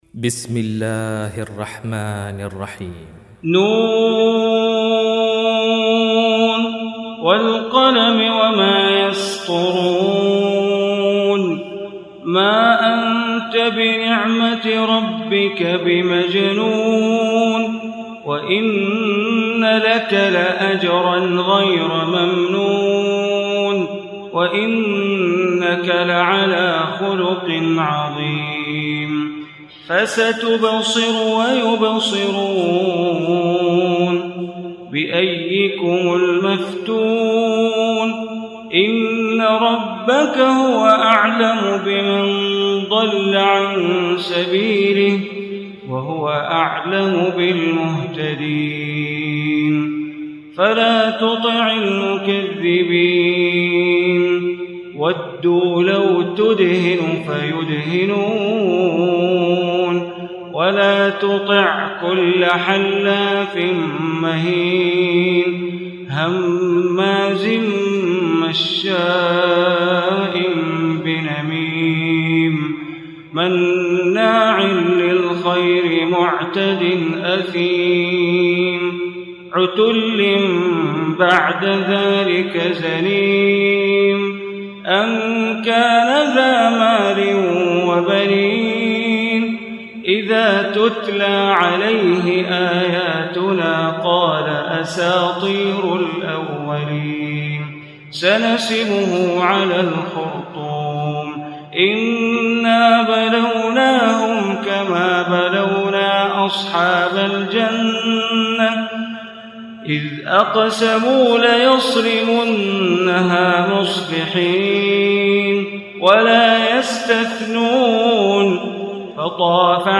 Surah Qalam Recitation by Sheikh Bandar Baleela
Surah Qalam, listen online mp3 tilawat / recitation in Arabic recited by Imam e Kaaba Sheikh Bandar Baleela.